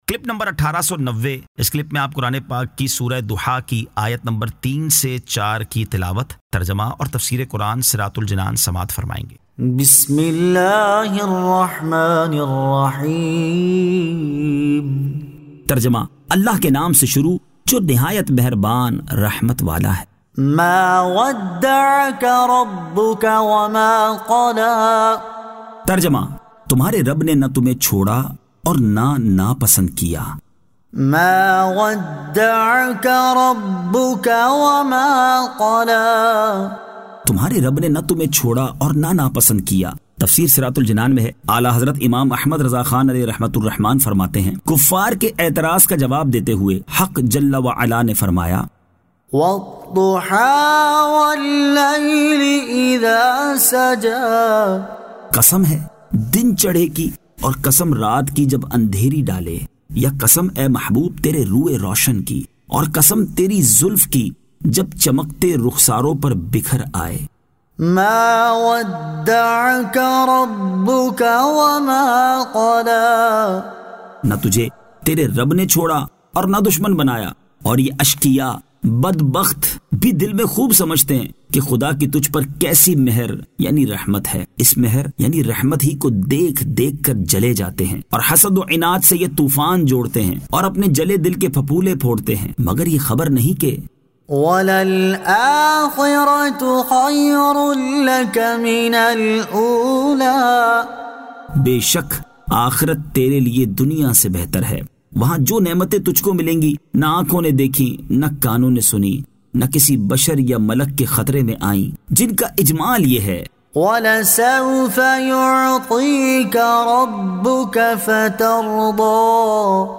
Surah Ad-Duhaa 03 To 04 Tilawat , Tarjama , Tafseer